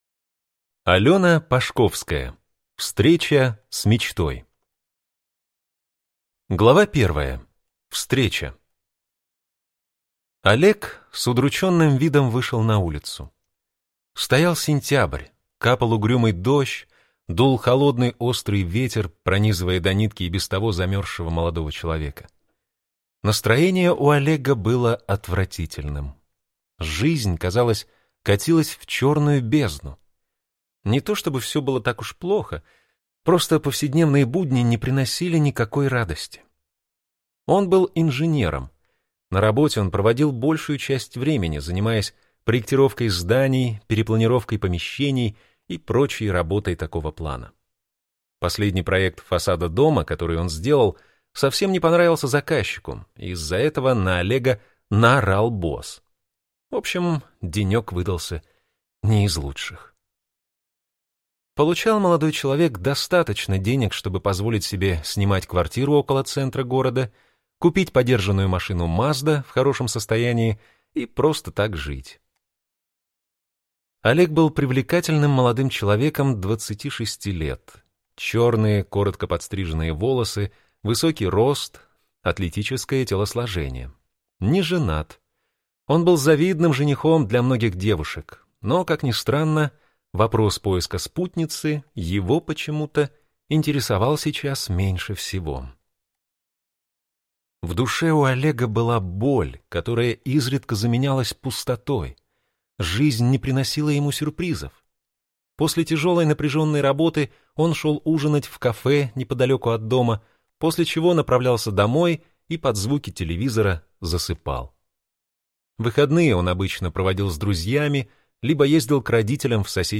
Аудиокнига Встреча с мечтой | Библиотека аудиокниг